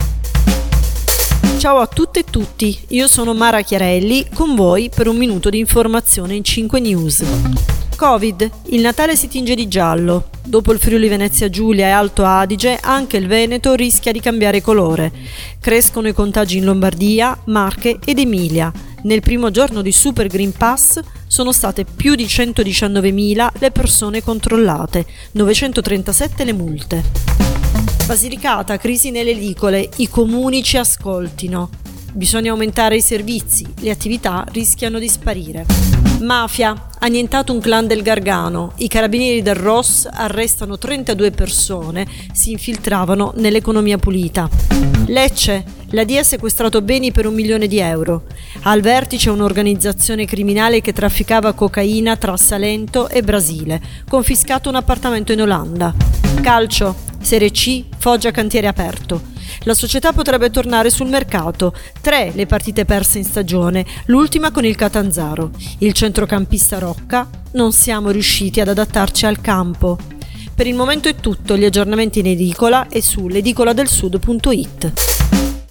Giornale radio alle ore 7